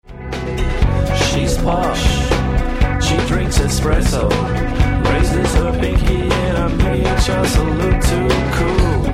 73k MP3 (9 secs, mono)